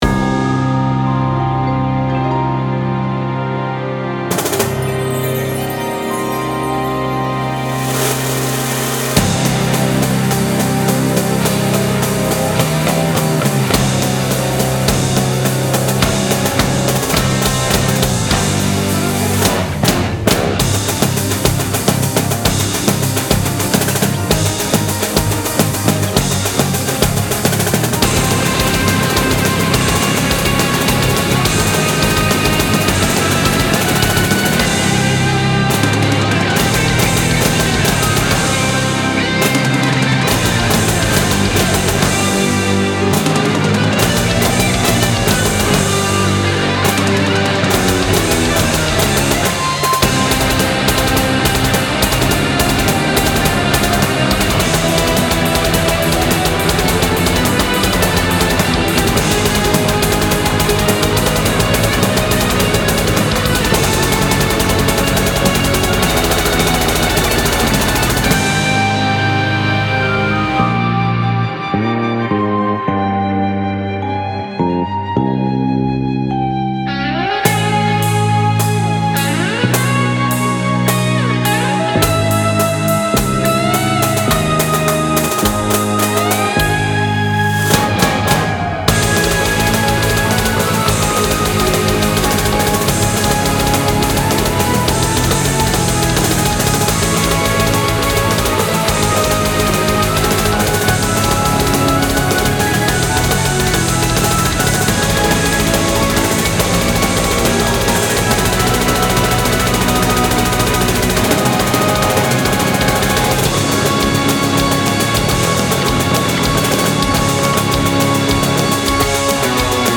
BPM105-210
Audio QualityPerfect (High Quality)